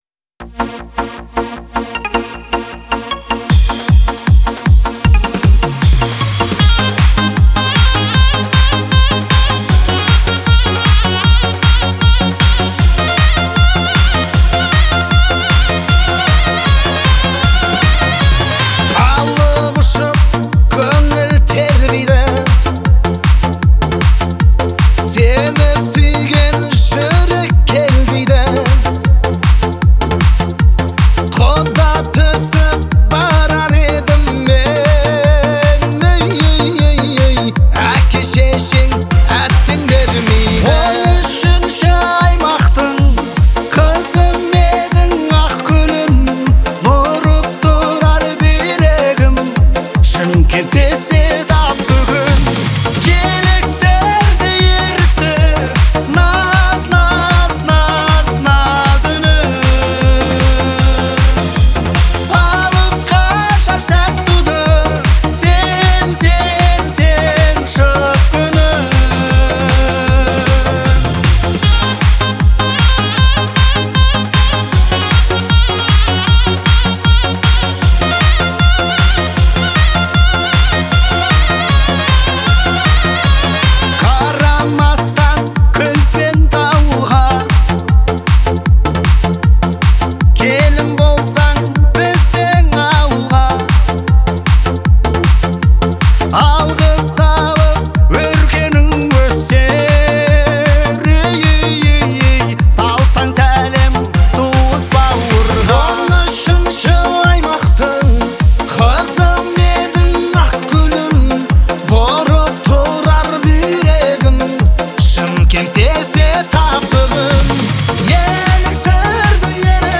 относится к жанру казахского фольклора и поп-музыки